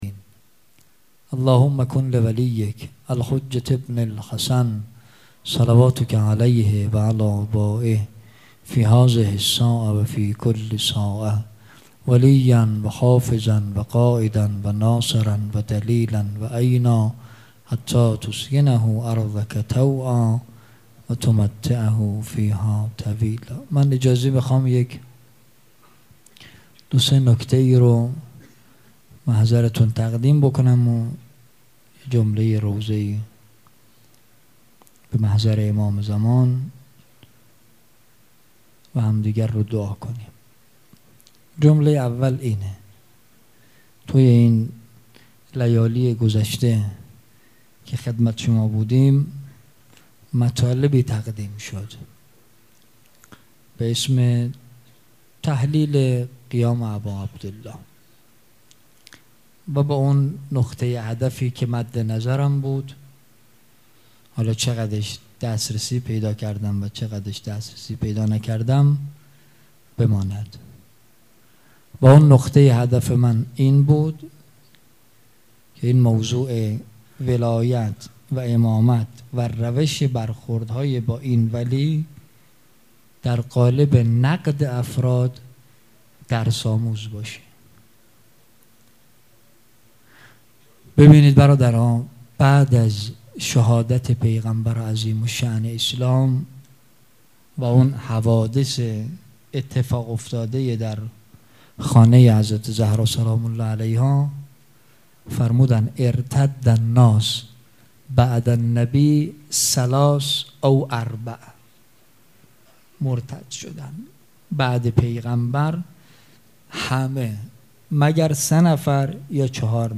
سخنرانی
مراسم عزاداری شب شام غریبان